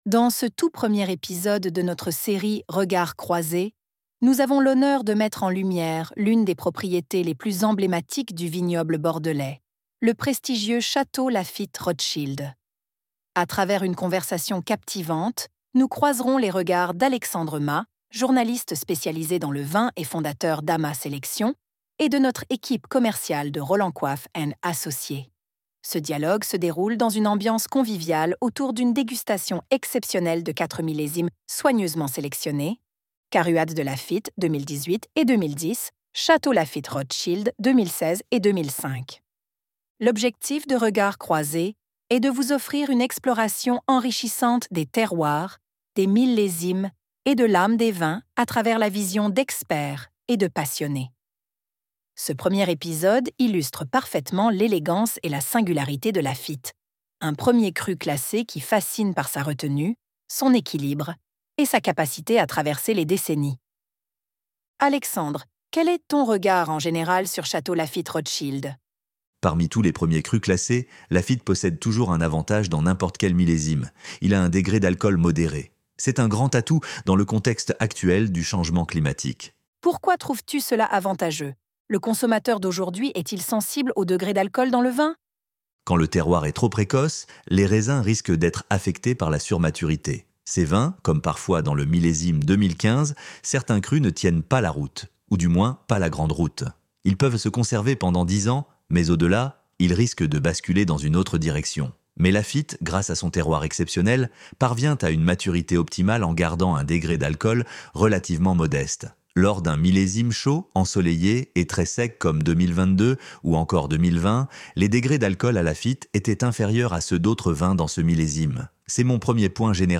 Ce dialogue se déroule dans une ambiance conviviale autour d’une dégustation exceptionnelle de quatre millésimes soigneusement sélectionnés :